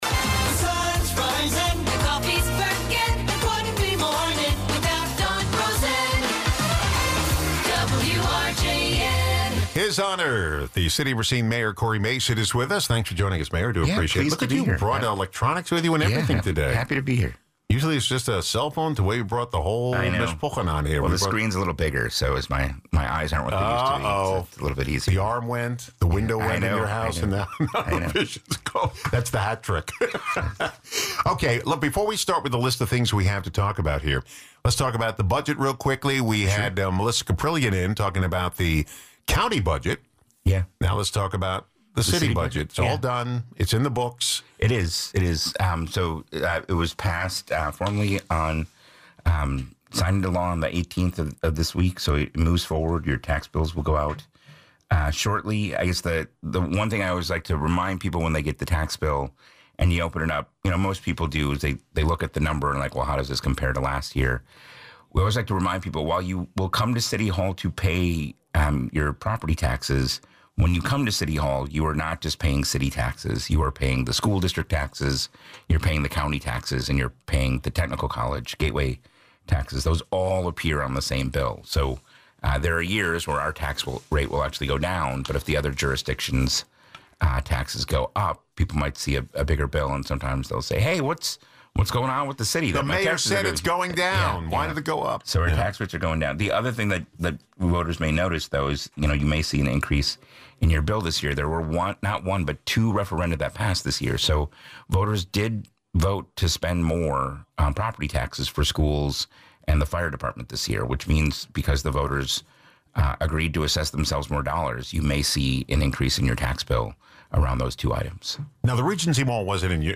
City of Racine Mayor Cory Mason discusses the new city budget and other topics of interest to Racine residents.